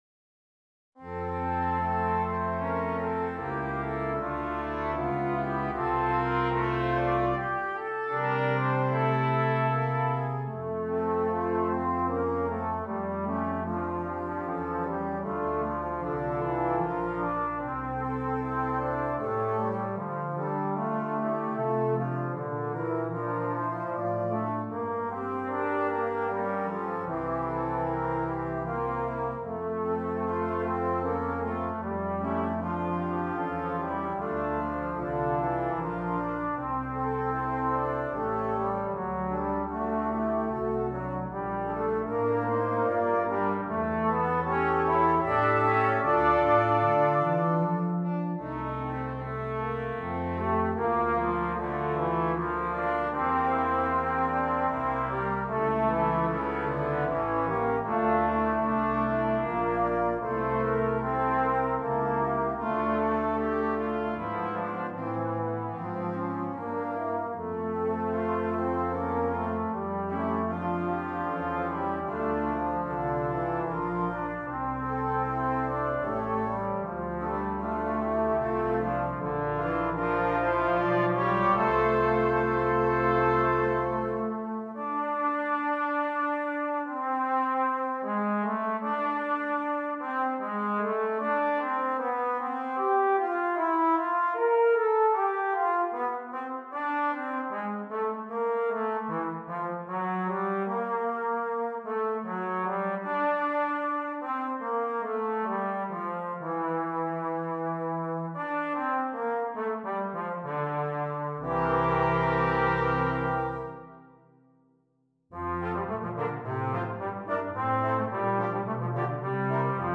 Brass Quintet
combining lyrical playing and technical passages